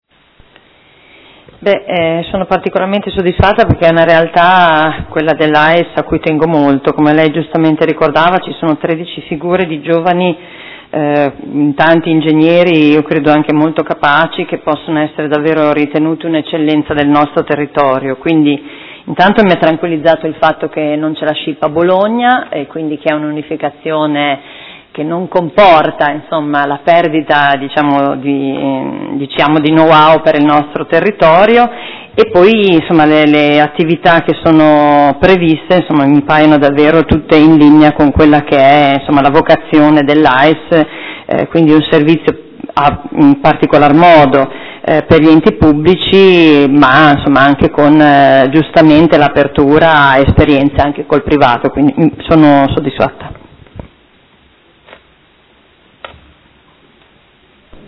Seduta del 5/5/2016 Replica a risposta Assessore. Interrogazione della Consigliera Arletti (P.D.) avente per oggetto: Agenzia per l’energia e lo sviluppo sostenibile – quale futuro si sta costruendo?